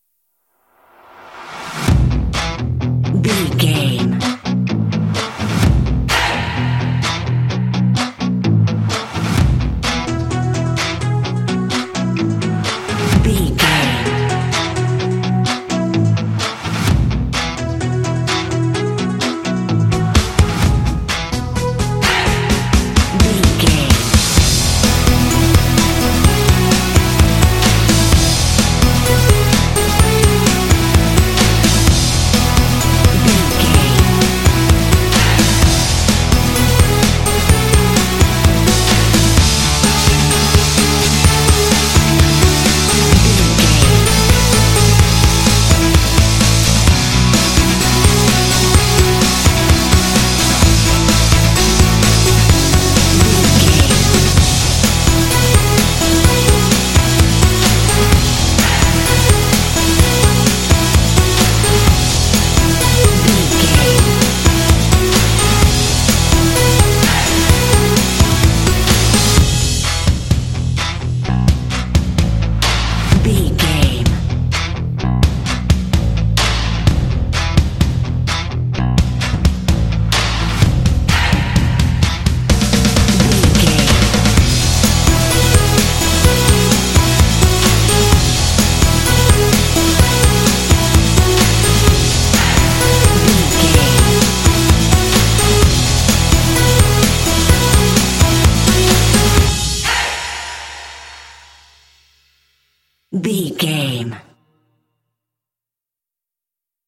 This indie track contains vocal “hey” shots.
Uplifting
Ionian/Major
lively
cheerful
drums
bass guitar
electric guitar
percussion
synthesiser
synth-pop
alternative rock
indie